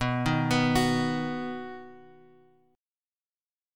B chord {x 2 1 x 0 2} chord
B-Major-B-x,2,1,x,0,2-8.m4a